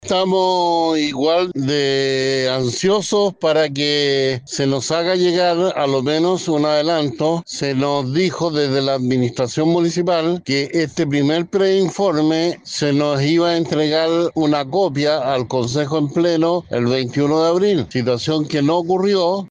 En ese contexto, Dante Iturrieta, edil de Valparaíso, remarcó que se generó un clima de incertidumbre al interior de la instancia, debido al retraso en la recepción de los datos comprometidos.